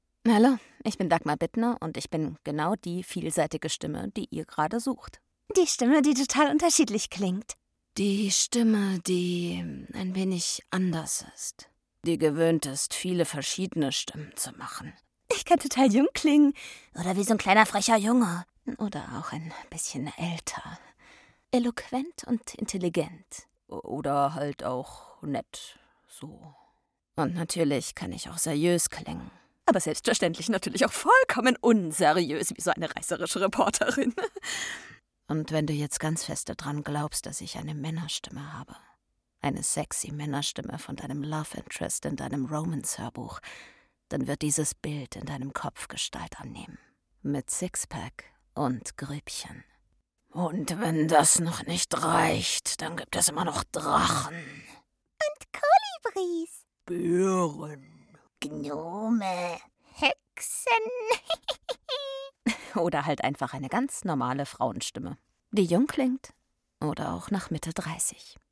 sehr variabel, markant
Jung (18-30)
Fränkisch
Audio Drama (Hörspiel), Audiobook (Hörbuch), Audioguide, Comedy, Commercial (Werbung), Game, Narrative, Trick, Tale (Erzählung)